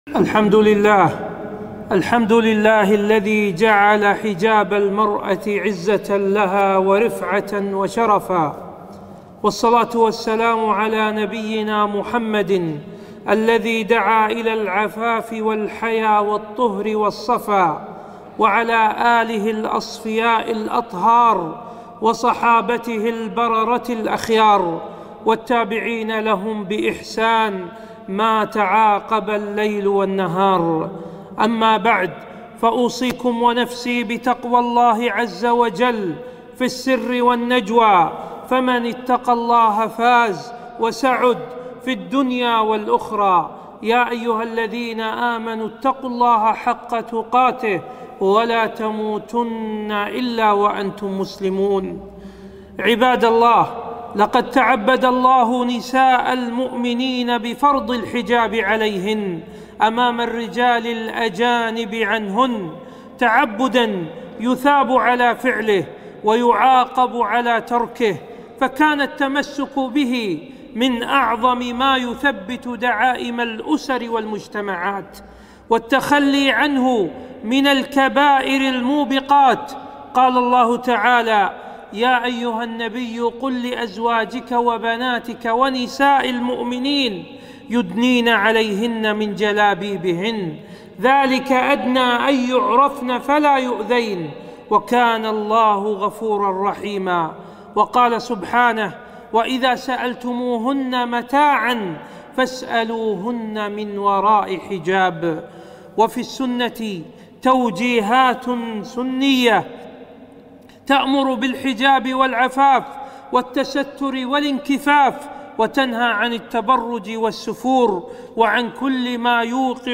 خطبة - الحرب على الحجاب في الهند